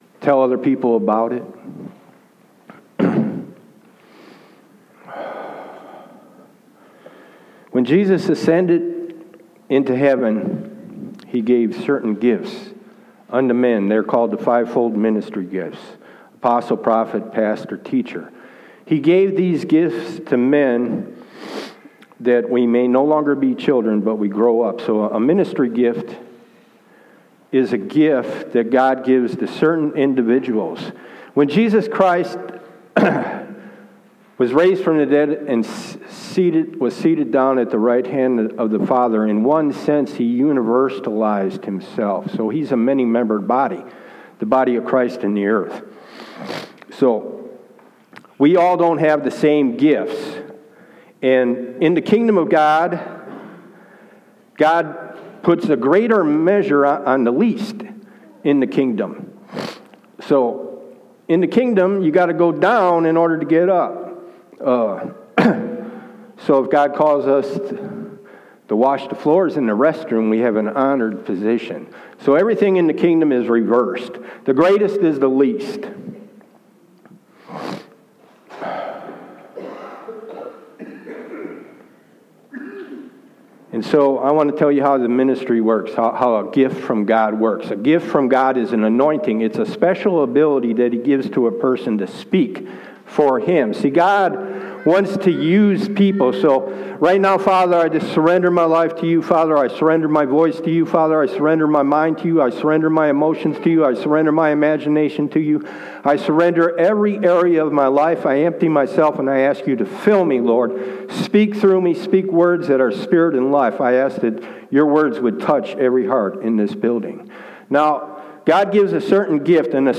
This is my first Audio on my website I did back in 2019 when I co-pastored a church and it was an evangelical message, because a lot of people in the service were not saved, God moved mightily even though I was physically sick.
I did not have an outline, just some bible verses on the bulletin they gave out to the people.